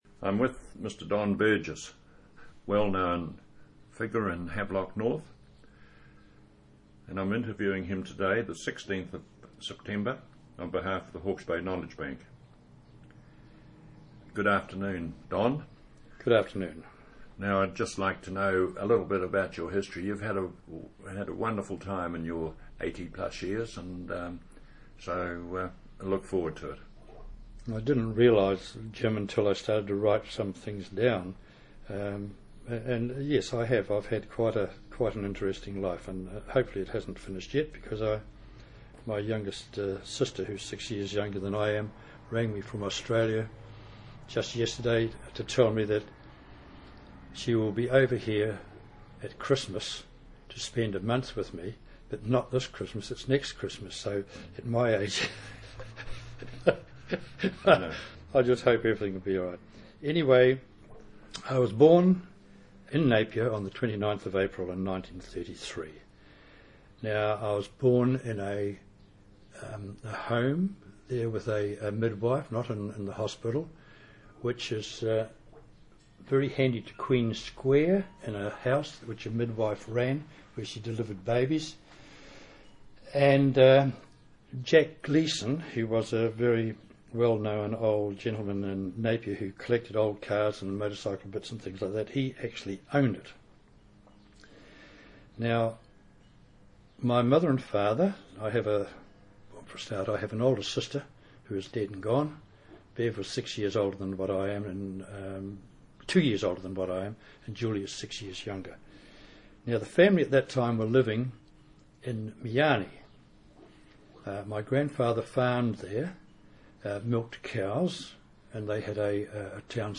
This oral history has been edited in the interests of clarity.